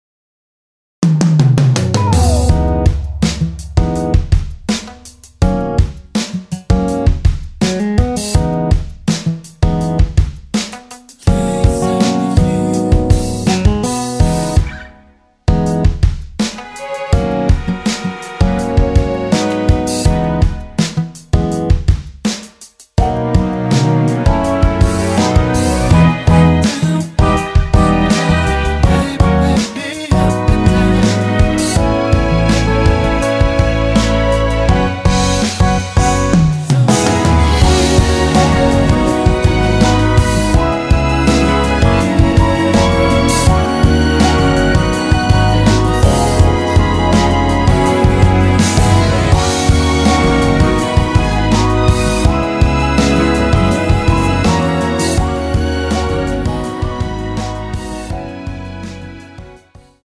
원키 코러스 포함된 MR 입니다.(미리듣기 참조)
앞부분30초, 뒷부분30초씩 편집해서 올려 드리고 있습니다.
중간에 음이 끈어지고 다시 나오는 이유는